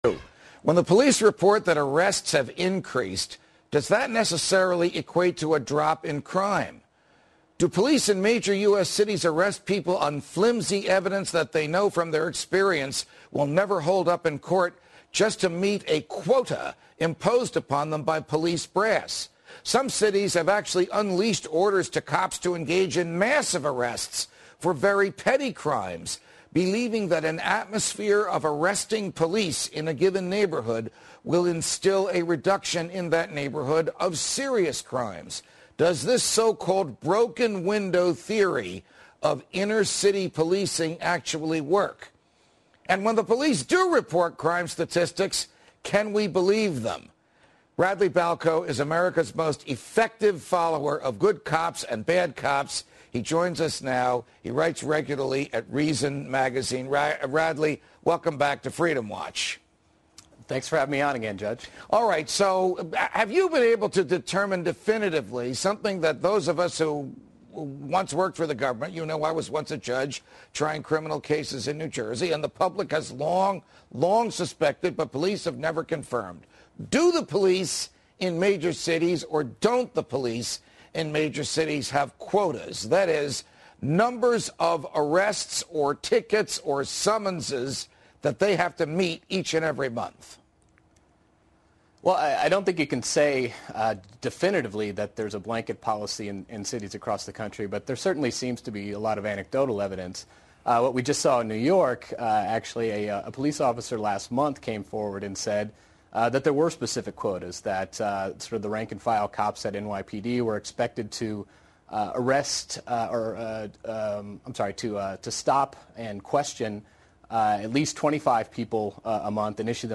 On March 9, 2010, Reason Senior Editor Radley Balko appeared on Fox News Freedom Watch with Judge Andrew Napolitano to discuss inner-city policing strategies.